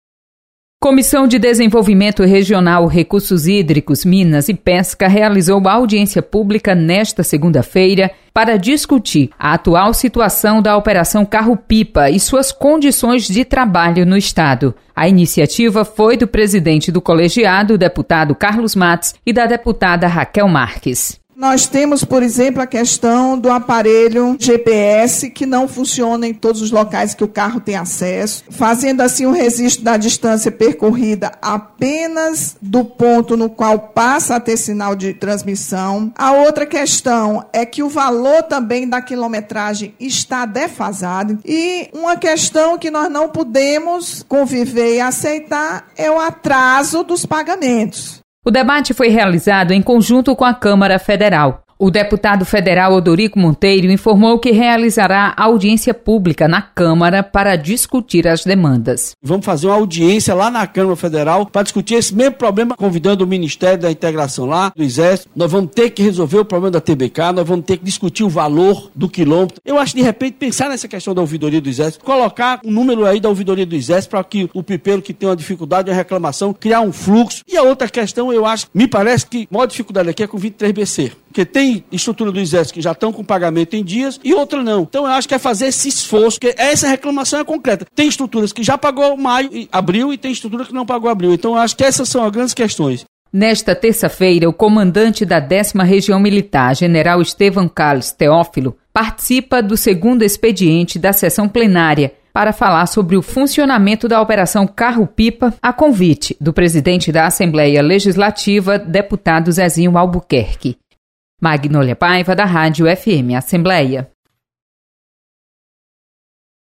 Publicado em Notícias